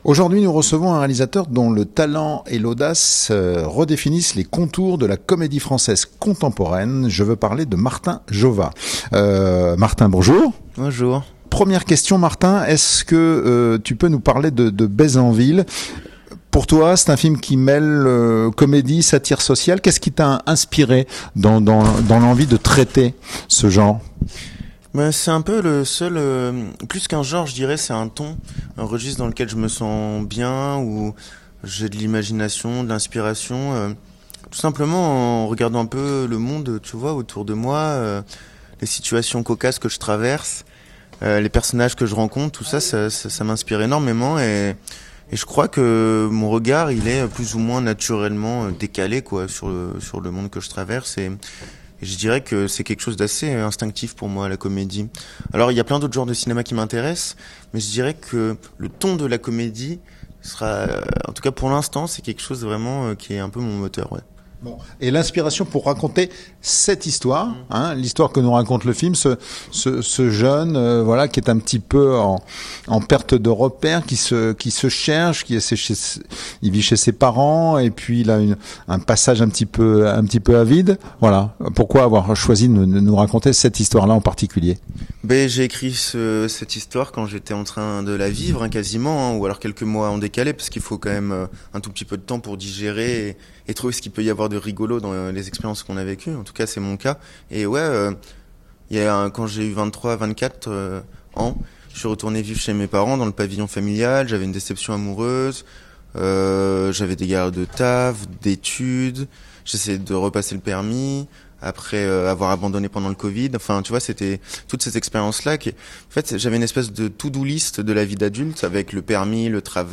Instinct, oui, mais pas improvisation.